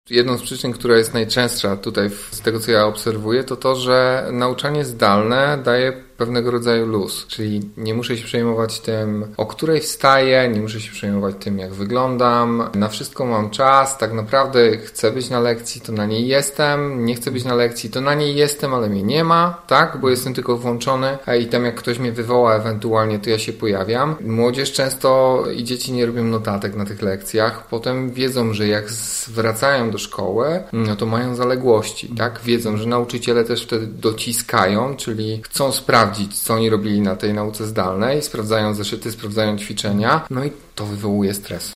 pedagog i psychoterapeuta.